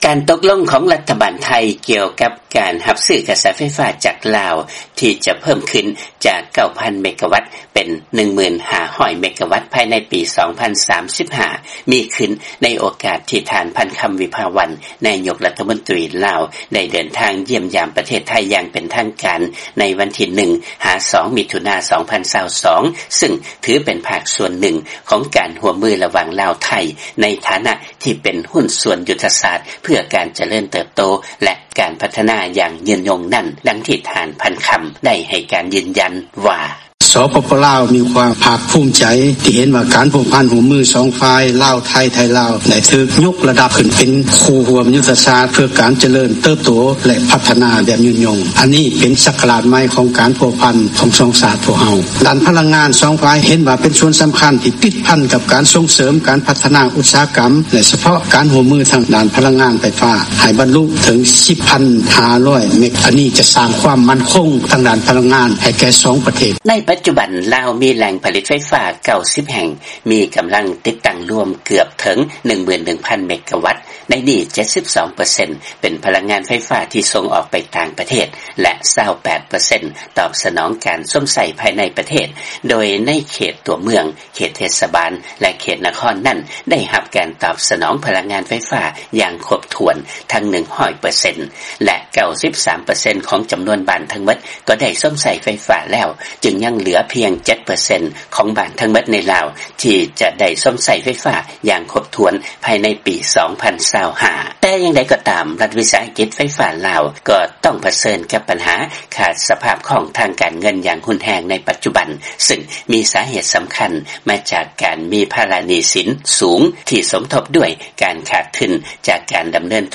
ຟັງລາຍງານ ລັດຖະບານ ໄທ ຕົກລົງຈະຮັບຊື້ພະລັງງານໄຟຟ້າຈາກລາວເພີ່ມຂຶ້ນເປັນ 10,500 ເມກະວັດພາຍໃນປີ 2035